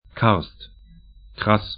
Pronunciation
Karst karst Kras kras sl Gebiet / region 45°48'N, 14°00'E